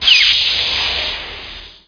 DOOR.WAV